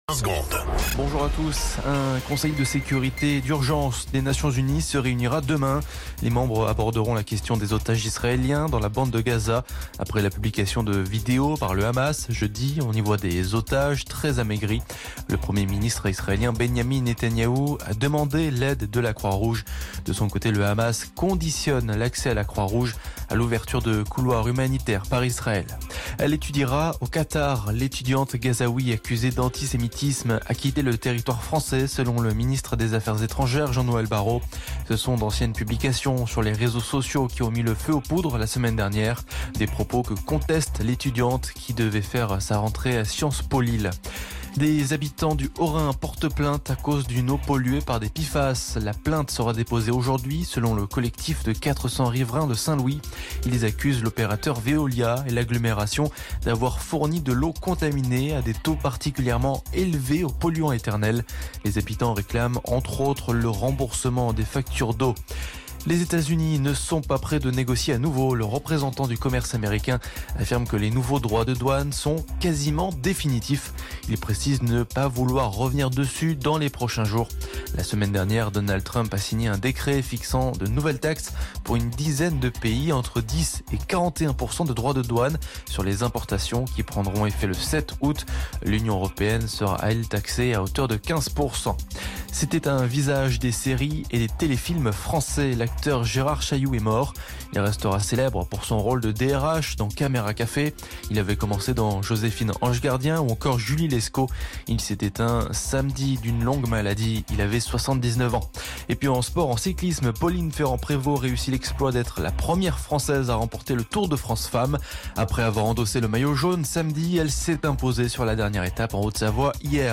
Flash Info National 04 Août 2025 Du 04/08/2025 à 07h10 .